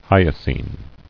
[hy·o·scine]